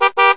BEEP_08.WAV